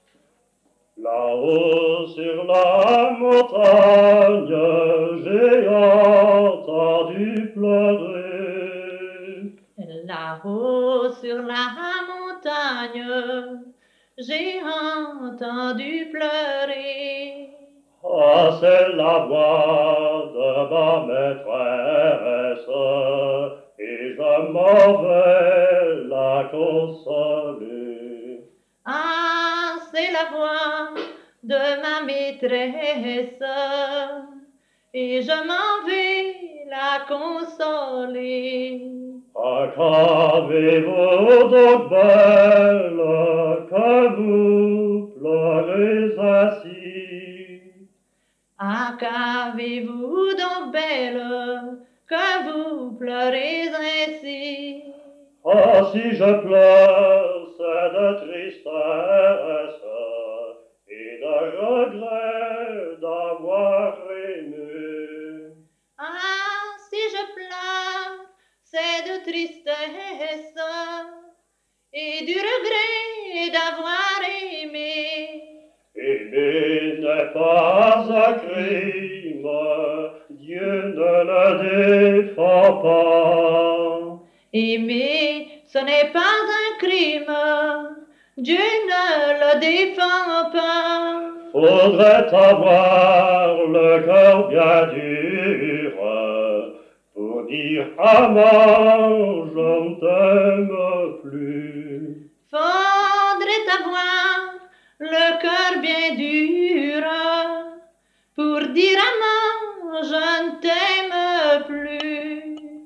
Genre : chant
Type : chanson narrative ou de divertissement
Lieu d'enregistrement : Morville
Support : bande magnétique